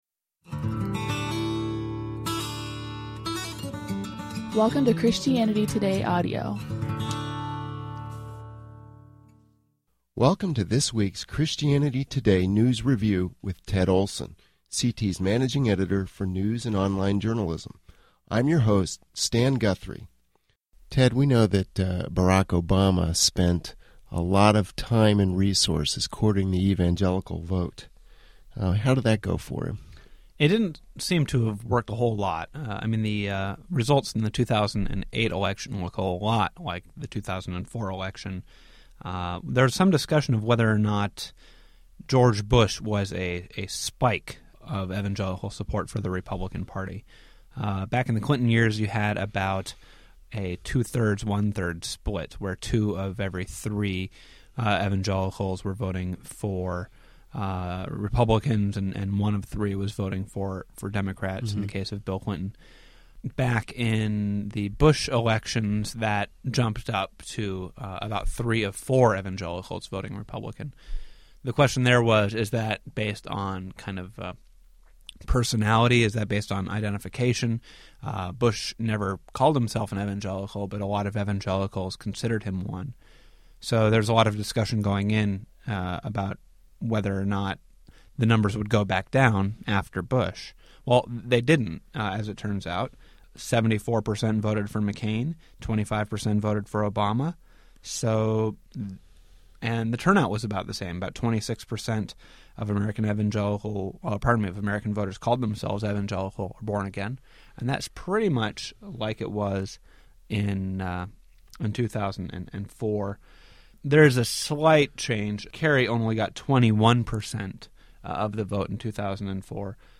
podcast discussion